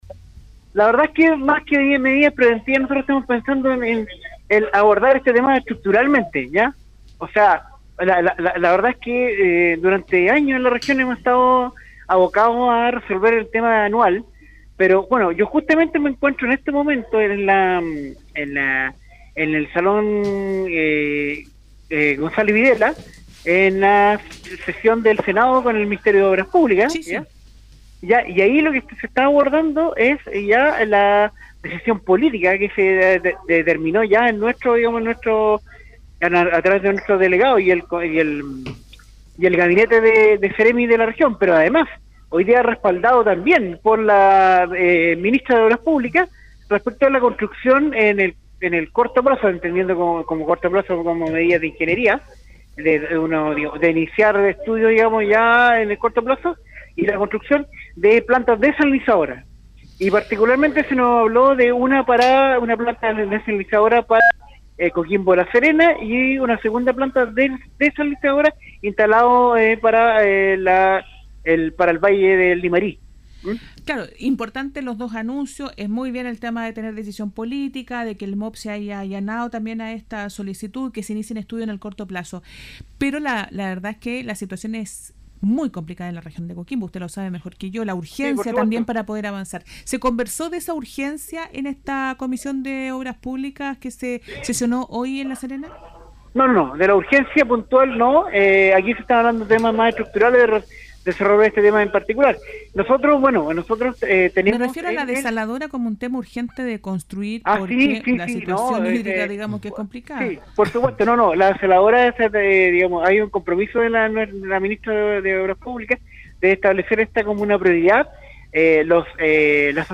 El Seremi de Agricultura de la región de Coquimbo, Cristian Alvarez, confirmó en conversación con Radio Guayacán, la ratificación de dos desaladoras multipropósito para las Provincias de Elqui y Limarí. En el caso de la primera entregaría hasta 1,700 litros por segundo y la de Limarí hasta 1.200 litros por segundo, pudiendo asegurar el agua para consumo humano y, eventualmente, el suministro para los sistemas de Agua Potable Rural.